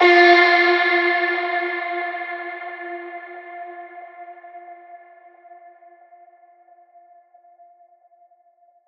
VR_vox_hit_daaaah_F.wav